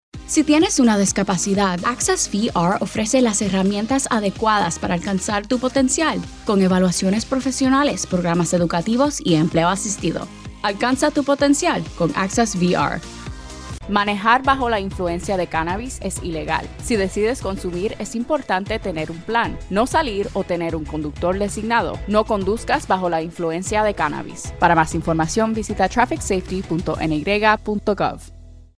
Spanish-speaking female voice actor
Neutral N. American, Puerto Rican, Bilingual